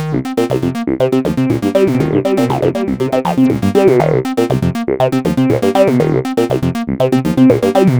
UR 303  xtra hard 1a.wav